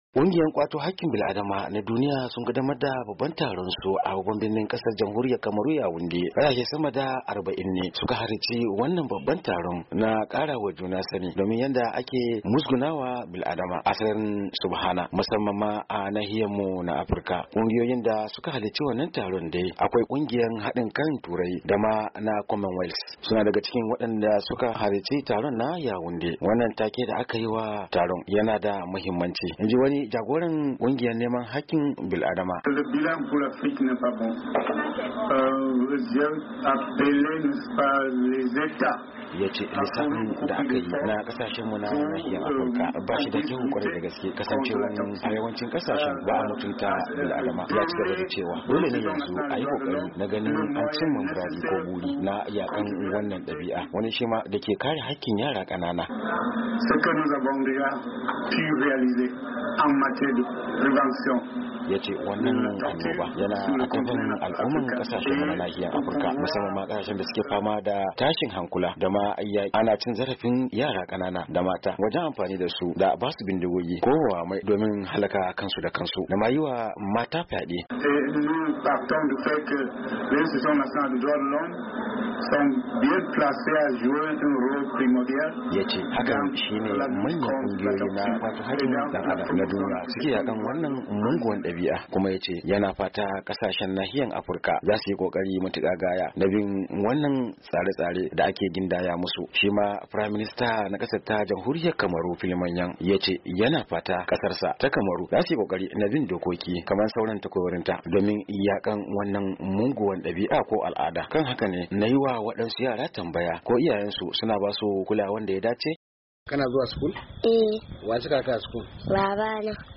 Wata mata da wakilin Sashen Hausa ya yi hira da ita tace yanzu an daina aure dole sabili da ana ba mata damar zaben wanda suke so su aura ta kuma bayyana cewa, zata bar ‘ya’yanta mata su zabi wadanda suke so su aura.
Ga cikakken rahoton.